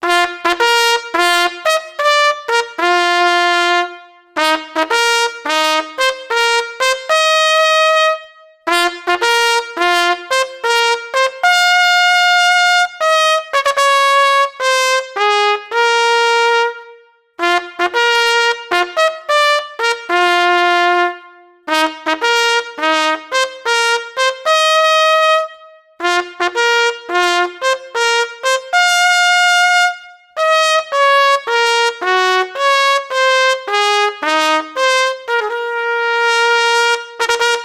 Jednocześnie harmonia i rytmika są zdecydowanie nowoczesne.
na pojedynczą trabkę